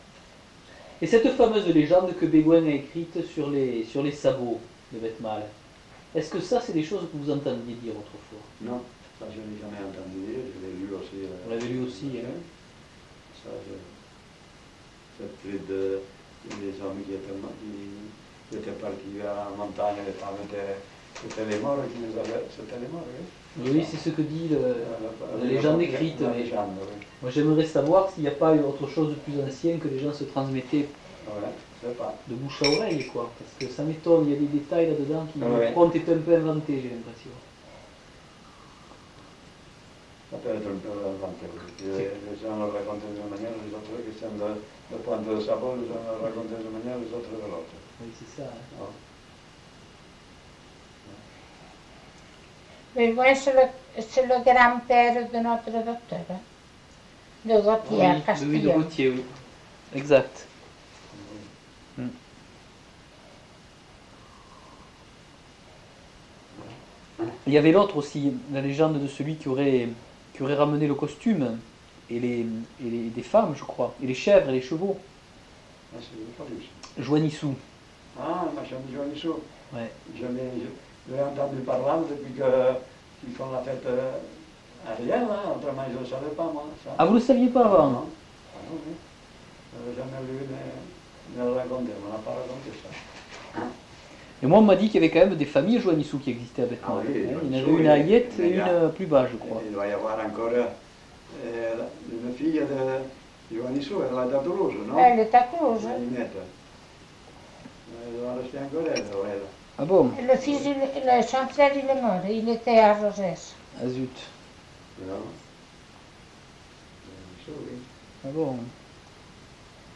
Lieu : Samortein (lieu-dit)
Genre : témoignage thématique